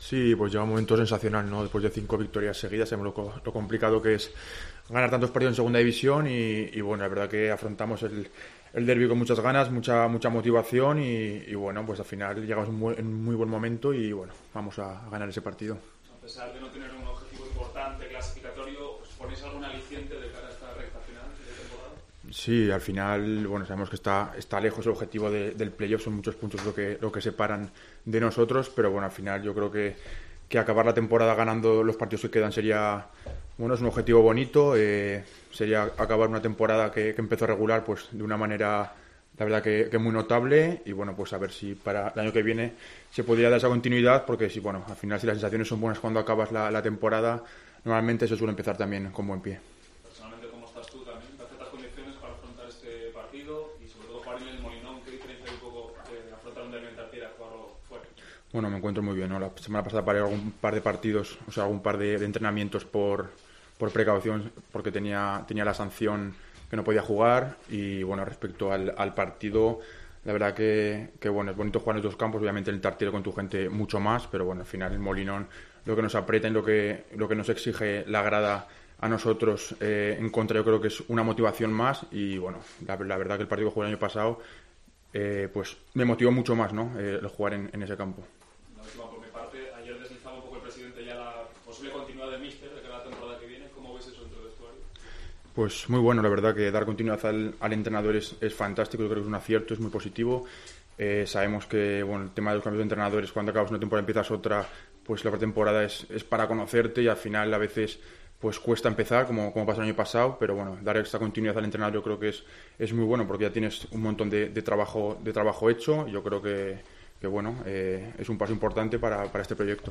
El central ha analizado la importancia del partido ante el Sporting en sala de prensa.